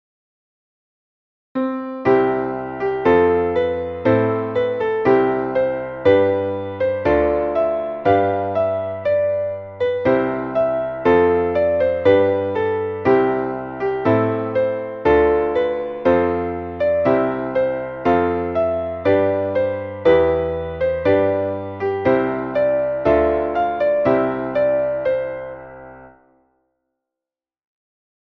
Traditionelles Weihnachtslied